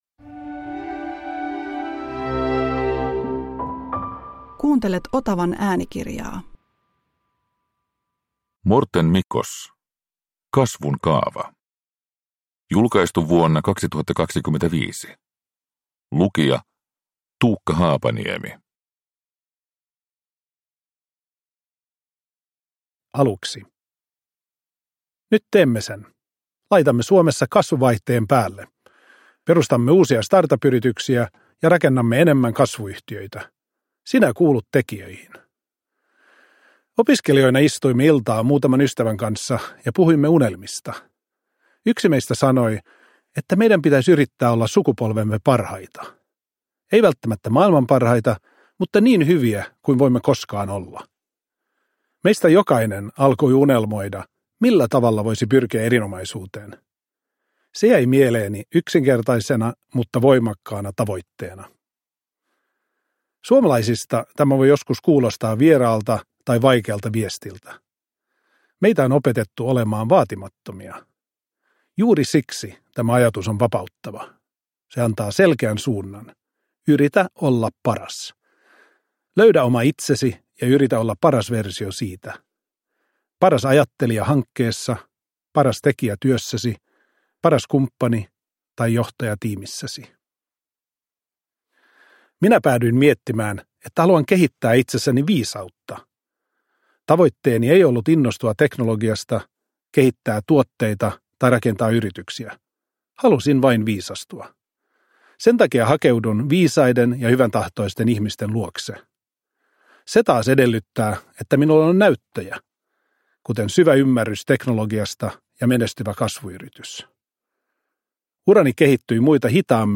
Kasvun kaava – Ljudbok